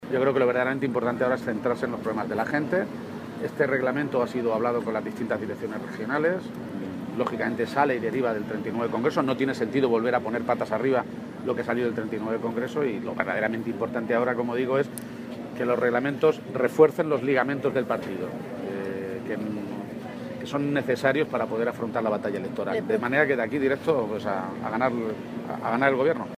Así lo reconocía a su llegada al Comité Federal del PSOE celebrado esta mañana en Aranjuez.
Cortes de audio de la rueda de prensa